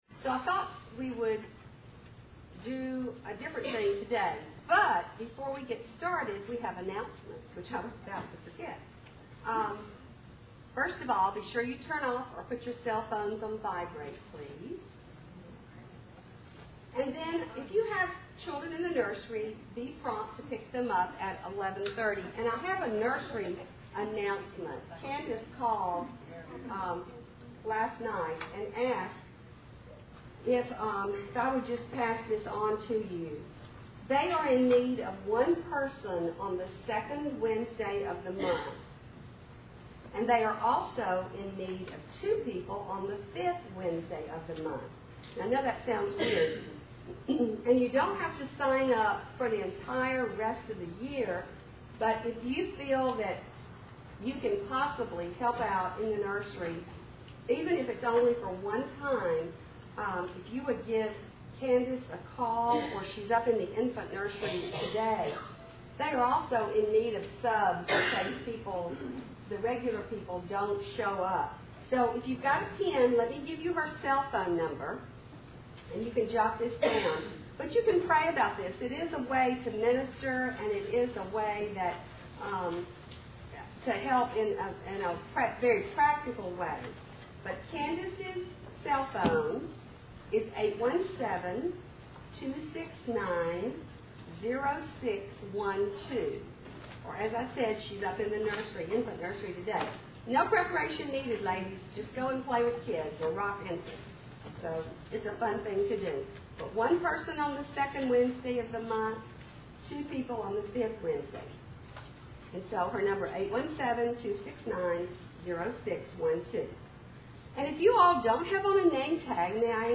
Women Women - Bible Study - The Attributes of God Audio ◀ Prev Series List Next ▶ Previous 4.